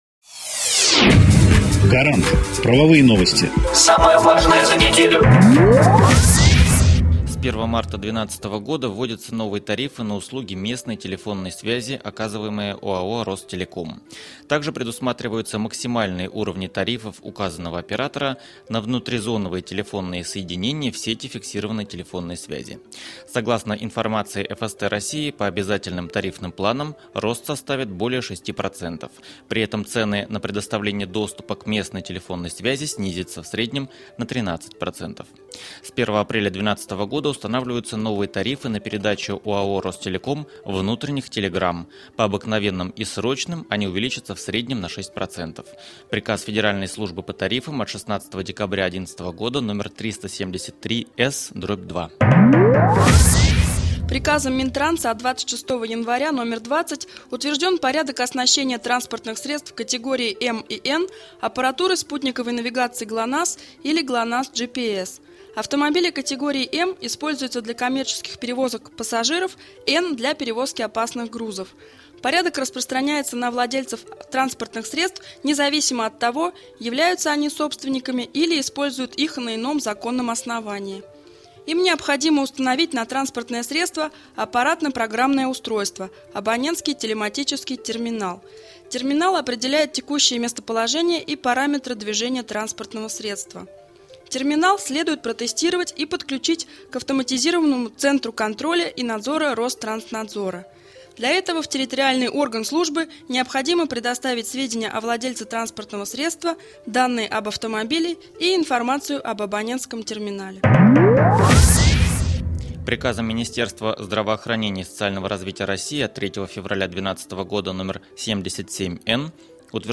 Аудионовости законодательства
Эксперты компании "Гарант" доступно и кратко рассказывают об актуальных законодательных нововведениях за последнюю неделю, акцентируя внимание на самом важном и интересном.